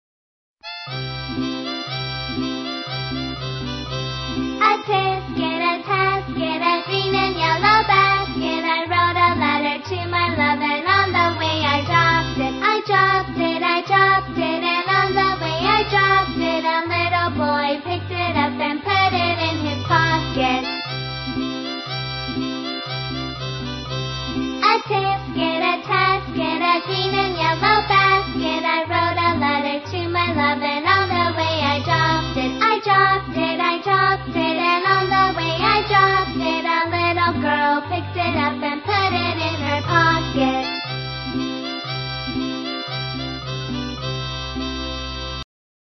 在线英语听力室英语儿歌274首 第10期:A tisket的听力文件下载,收录了274首发音地道纯正，音乐节奏活泼动人的英文儿歌，从小培养对英语的爱好，为以后萌娃学习更多的英语知识，打下坚实的基础。